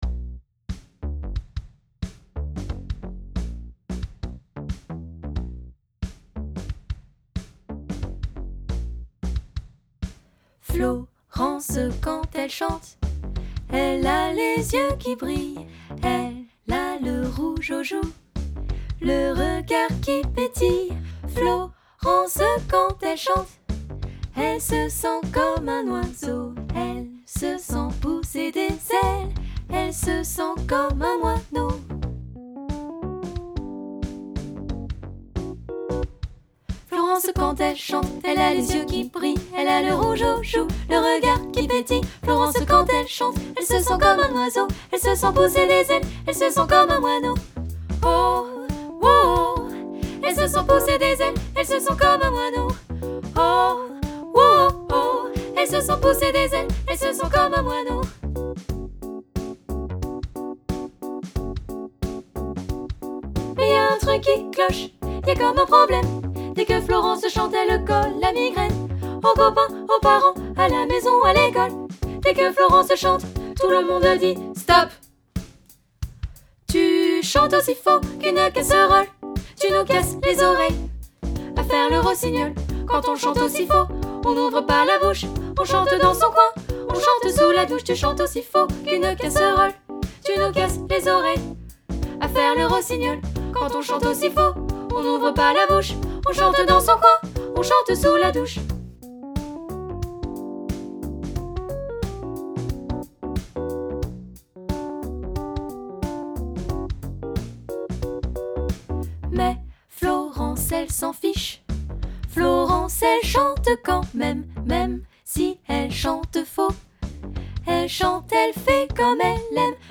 Genre :  Chanson
Style :  Avec accompagnement
Effectif :  PolyphonieVoix égales
Enregistrement piano et voix